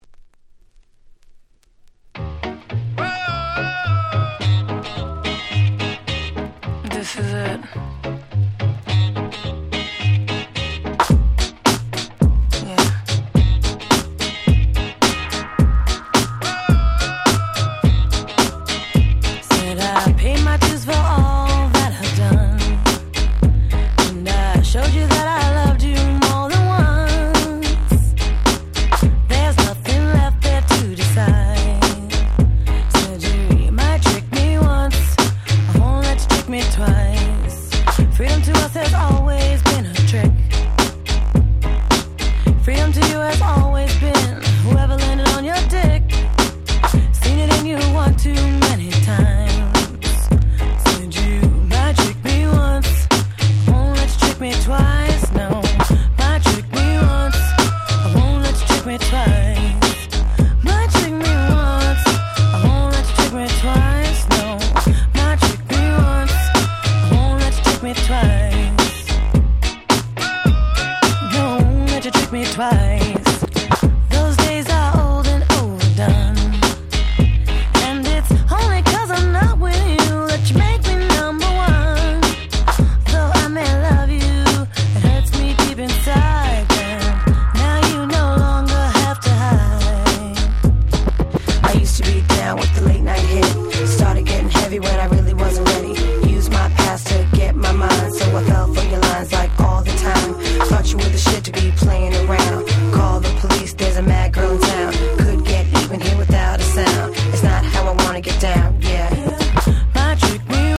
04' Super Hit R&B !!
軽快なBeatでつい体が動いてしまいます♪